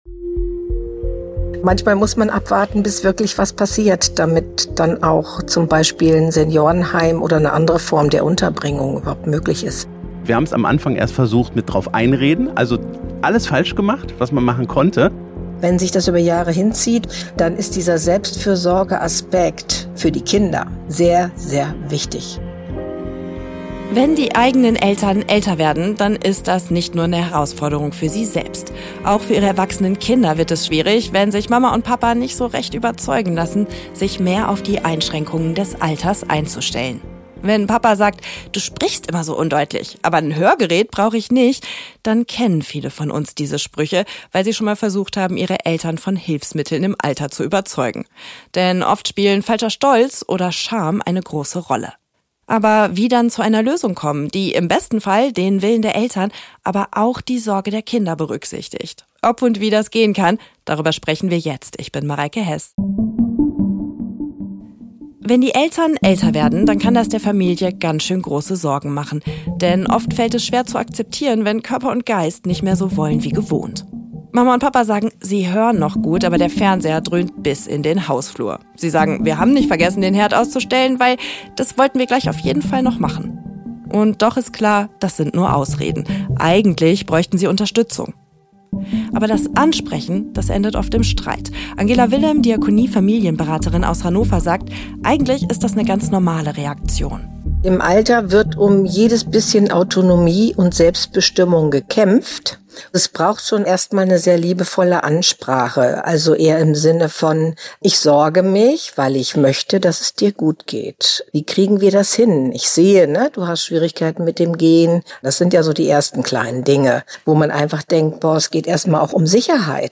„ffn - Die Kirche - Hilfe interaktiv“ ist eine wöchentliche Beratungssendung bei radio ffn in Zusammenarbeit mit der Diakonie. Menschen in Not aus Niedersachsen berichten im Gespräch auf sehr persönliche Weise, wie sie in scheinbar ausweglose Situationen geraten sind. Zusammen mit Beraterinnen und Beratern der Diakonie werden am konkreten Fall Auswege aus der Krise beschrieben.